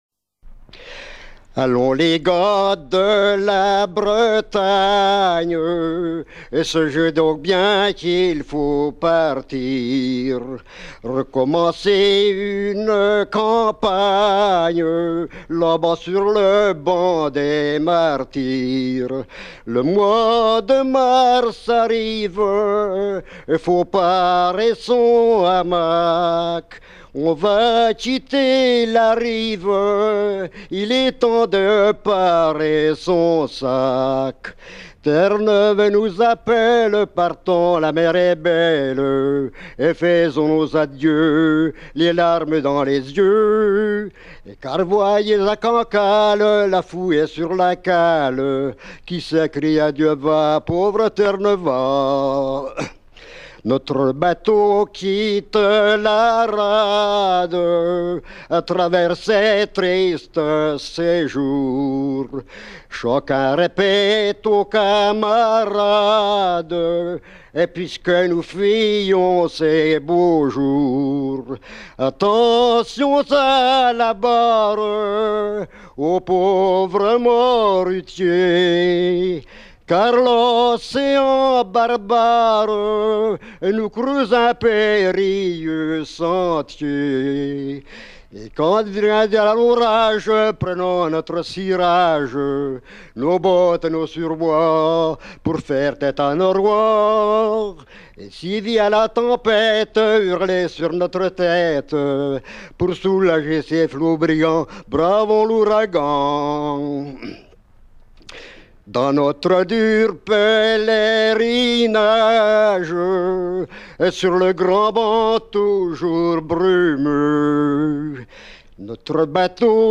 Version enregistrée en 1975, chanté par
Pièce musicale éditée